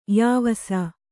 ♪ yāvasa